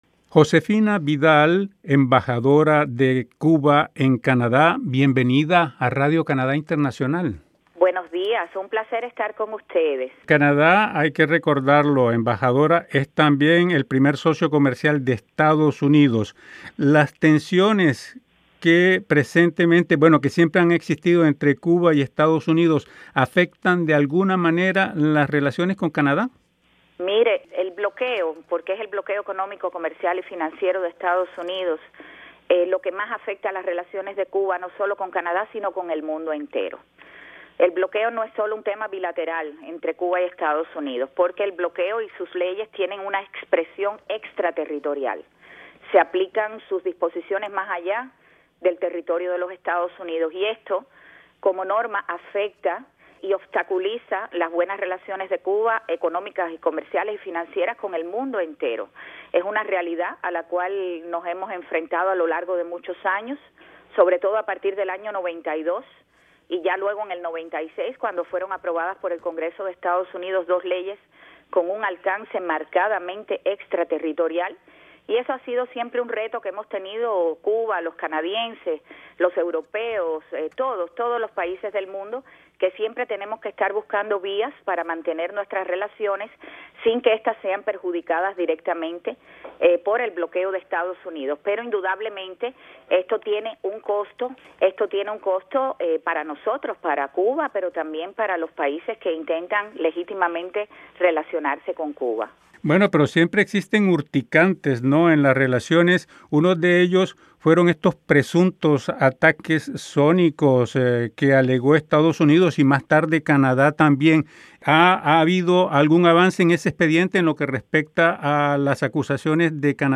ESCUCHE LA ENTREVISTA CON LA EMBAJADORA JOSEFINA VIDAL